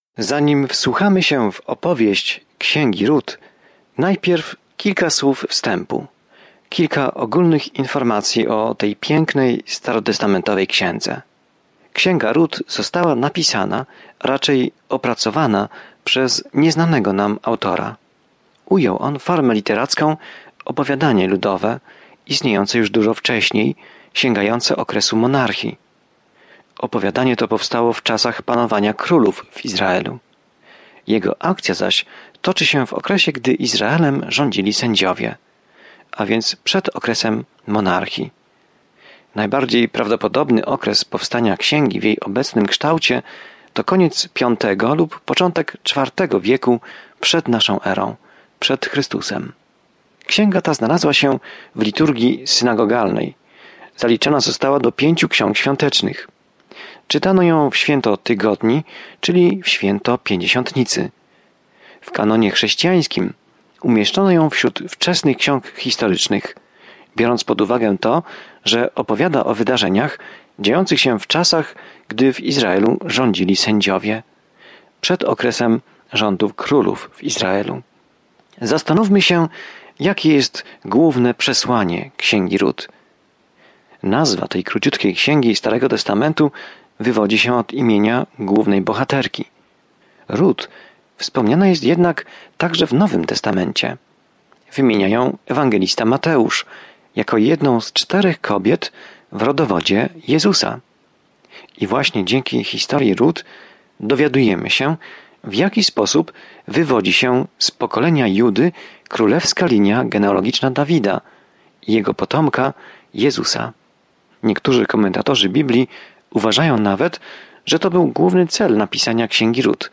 Pismo Święte Rut 1:1-6 Rozpocznij ten plan Dzień 2 O tym planie Rut, historia miłosna odzwierciedlająca miłość Boga do nas, opisuje długą historię – w tym historię króla Dawida… a nawet historię Jezusa. Codziennie podróżuj przez Rut, słuchając studium audio i czytając wybrane wersety słowa Bożego.